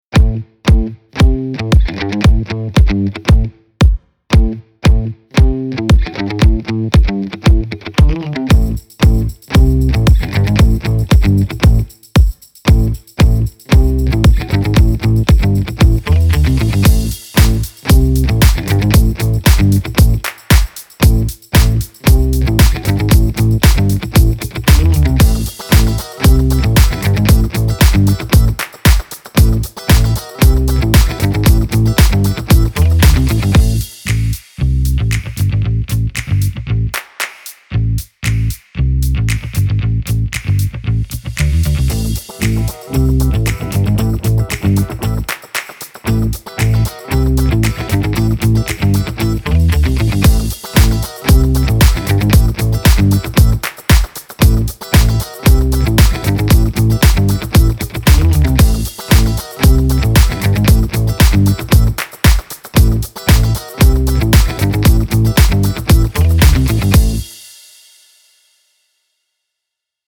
A-Funk-Full.mp3